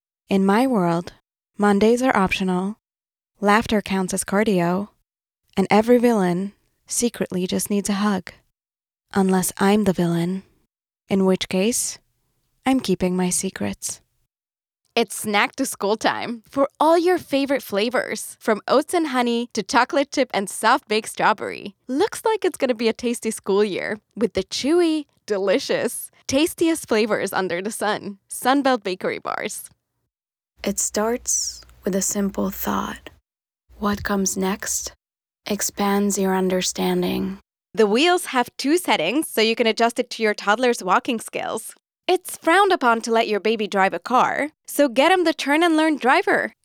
VO Reel